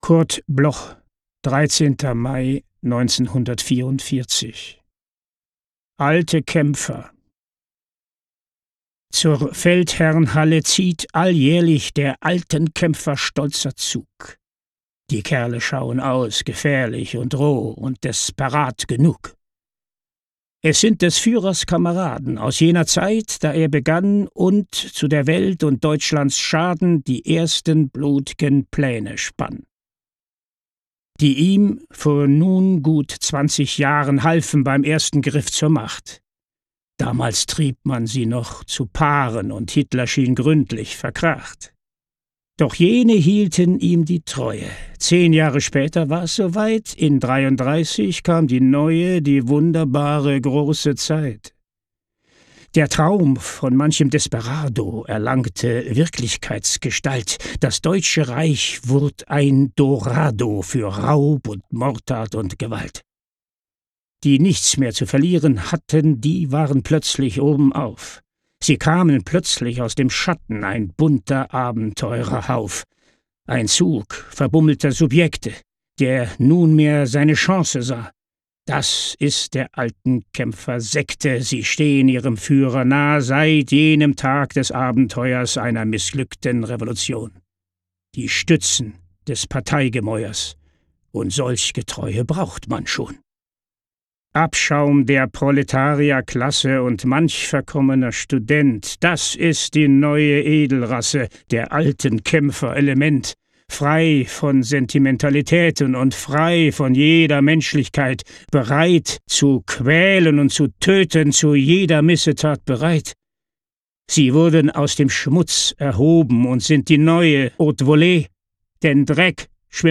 Aufnahme: Tonstudio Dortmund · Bearbeitung: Kristen & Schmidt, Wiesbaden